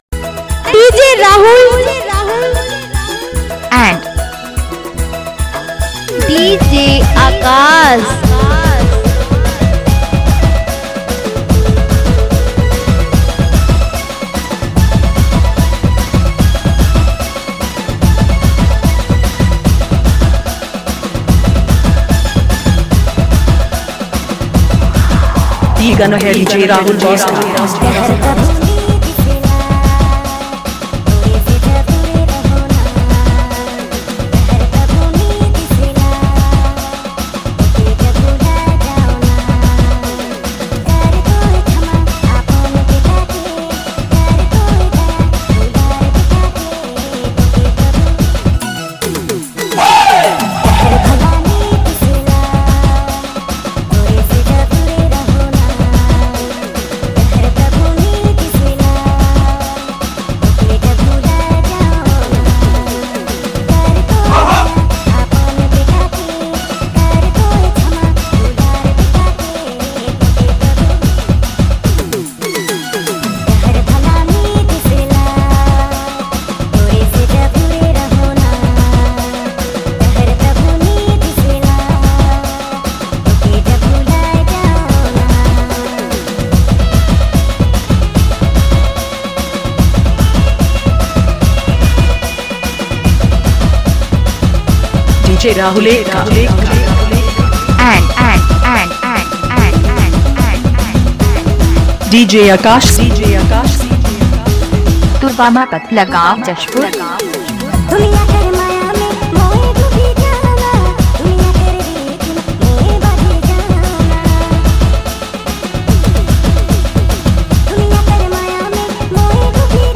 All Dj Remix
Christmas Dhanka Mix Song